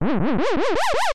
Sound effect from Super Mario RPG: Legend of the Seven Stars
Self-recorded using the debug menu
SMRPG_SFX_Geno_Boost.mp3